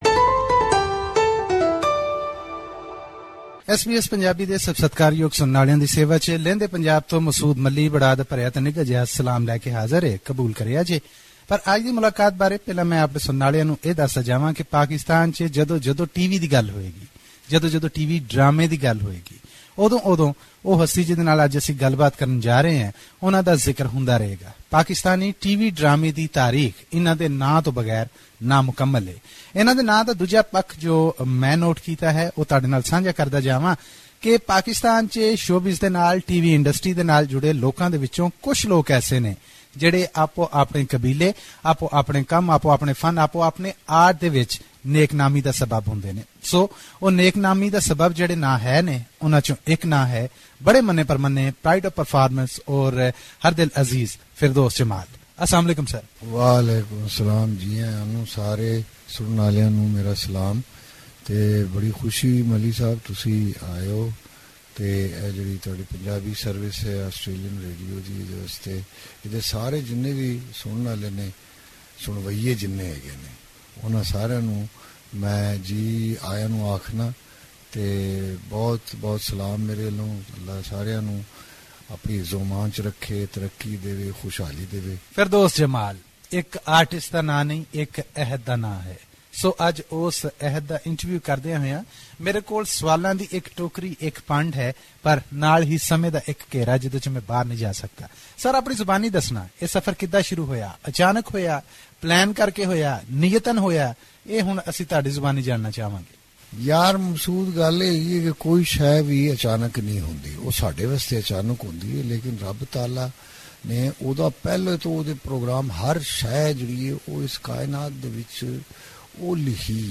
Our Pakistan based contributor brings us very encouraging talk with Janaab Ferdoos Jamal who spent most of his time in performing arts in various sectors of Pakistan TV and beyond. In this talk Ferdoos shares with us his journey, adventures and challenges that he faced while travelling this far.